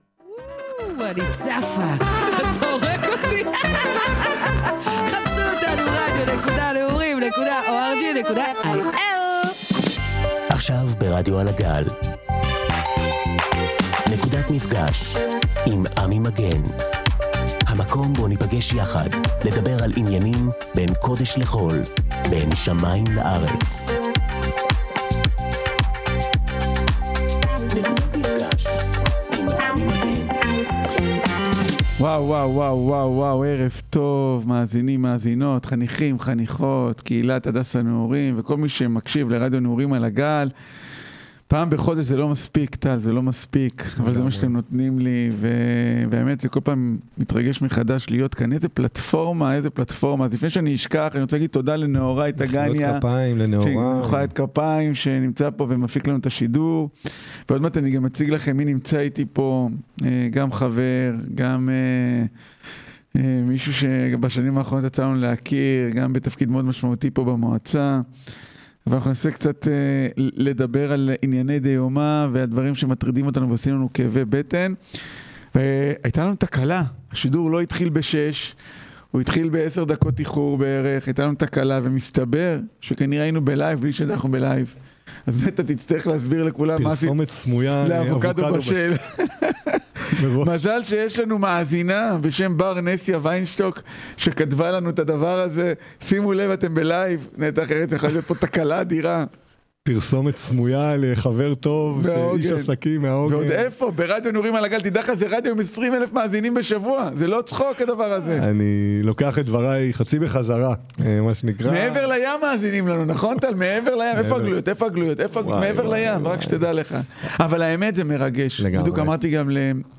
מארח את סגן ראשת מועצת עמק חפר – נטע אראלי